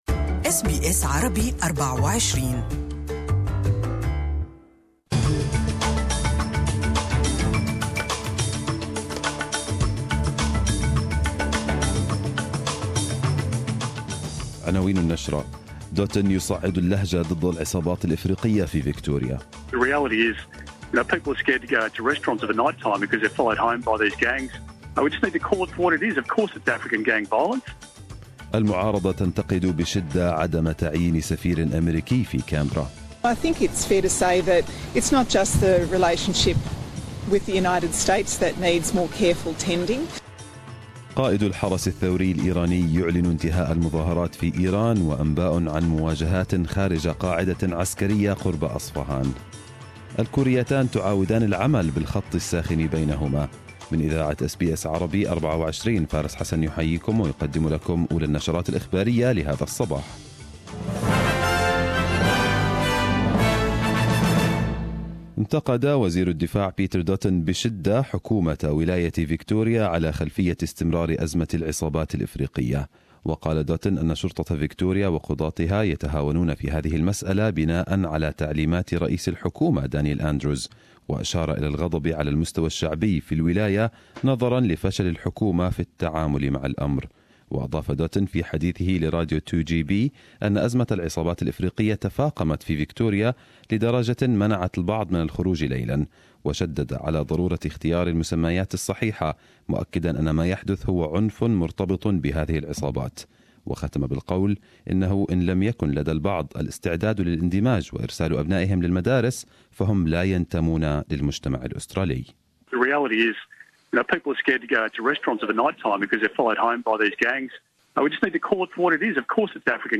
Arabic News Bulletin 04/01/2018